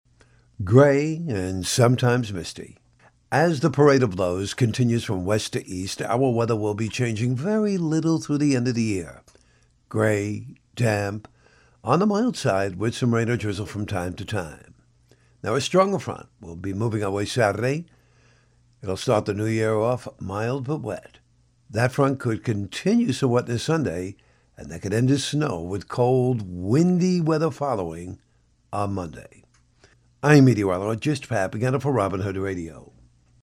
Your Robin Hood Radio Tri-State Forecast